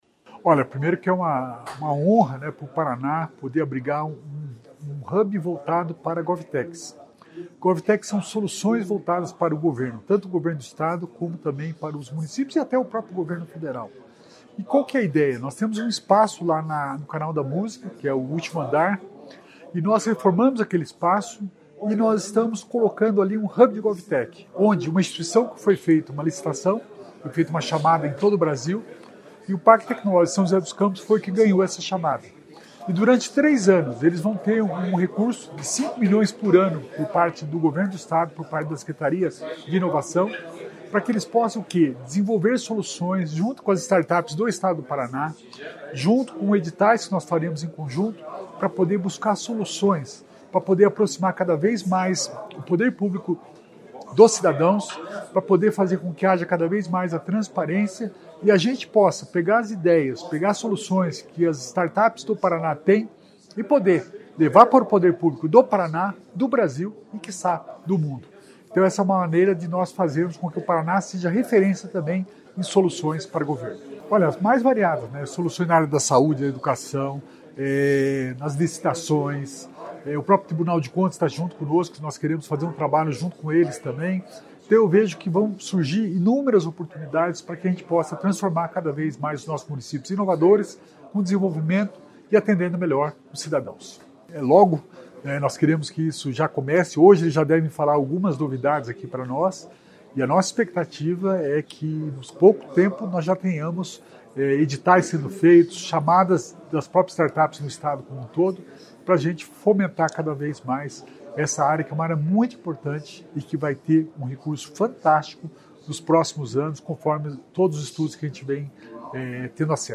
Sonora do secretário Estadual de Inovação e Inteligência Artificial, Alex Canziani, sobre o hub de GovTechs que vai ser instalado em Curitiba